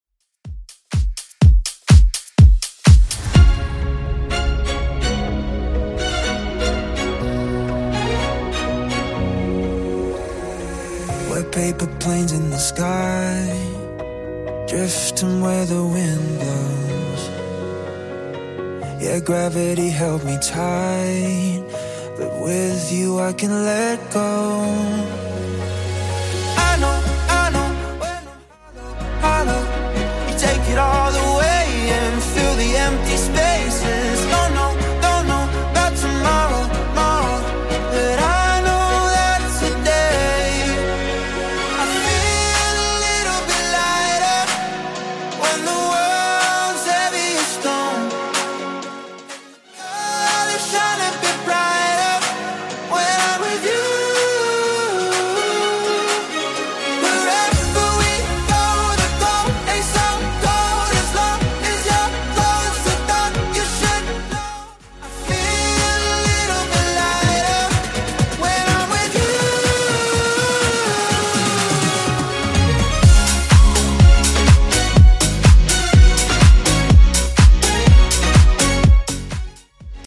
Genre: 80's Version: Clean BPM: 120